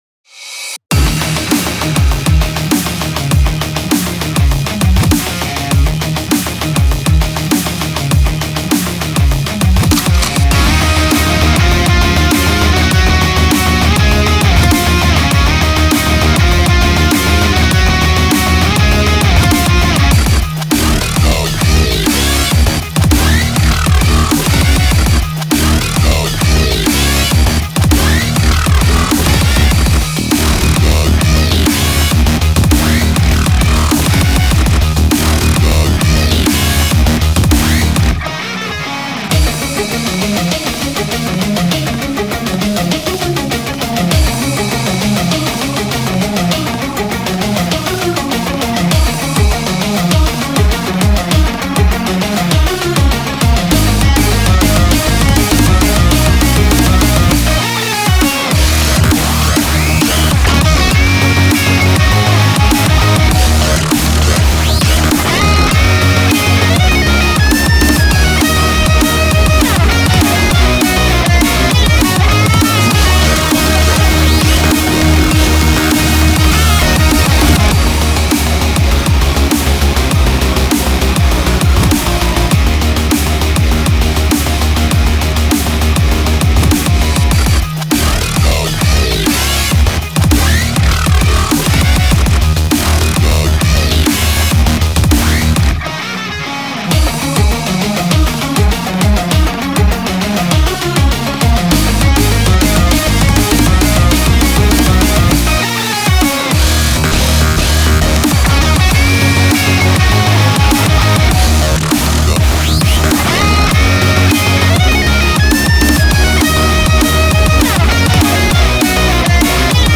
BPM100
Audio QualityPerfect (High Quality)
Comments[ROCKIN' BREAKS]